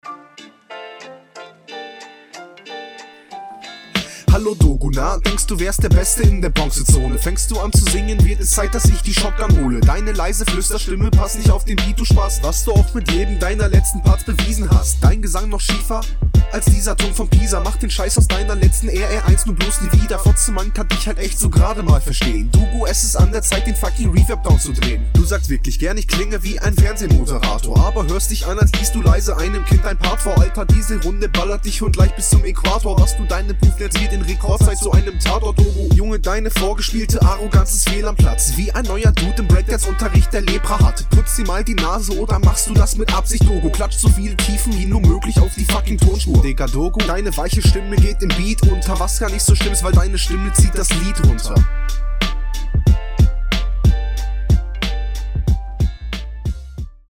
Kommst soundlich viel besser als ichs von dir gewohnt bin.